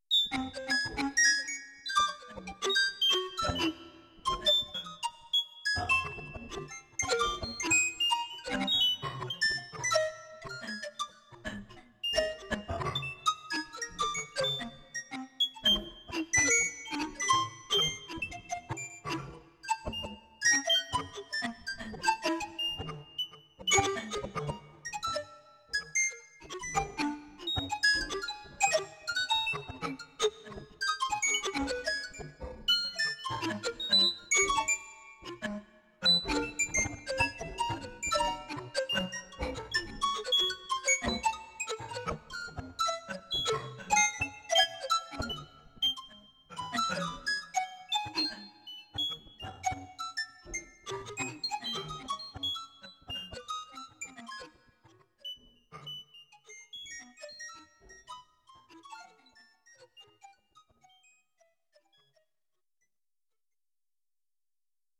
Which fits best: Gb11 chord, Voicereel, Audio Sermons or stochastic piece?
stochastic piece